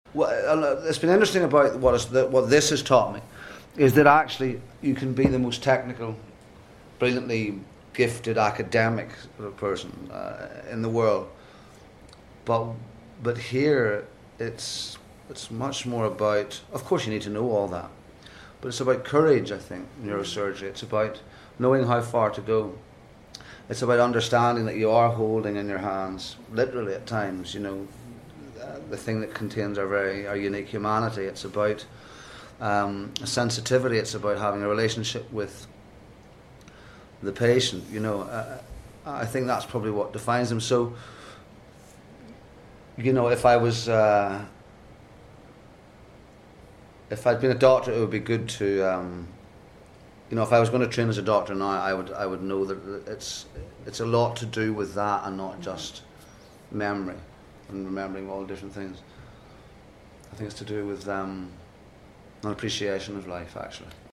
I met up with James in London before Christmas for a small round table interview ahead of his flight to New Zealand to star in The Hobbit.